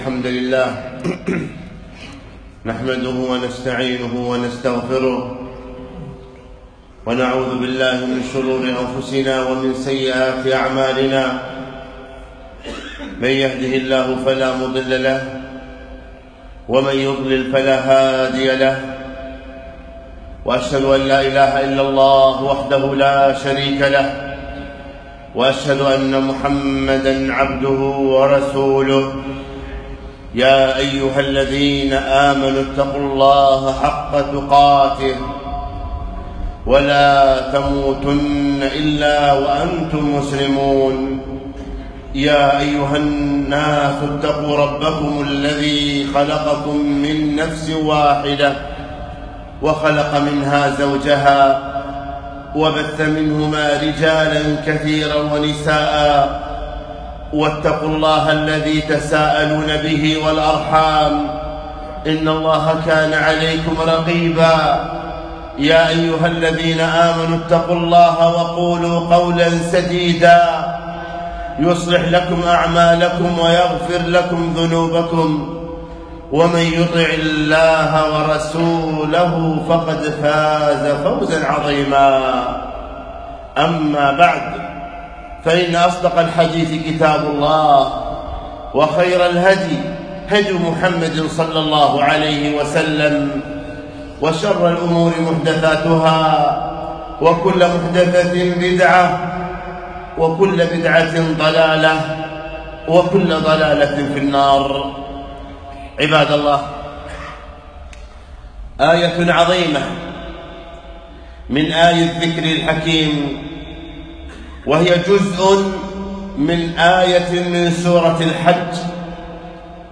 خطبة - وافعلوا الخير لعلكم تفلحون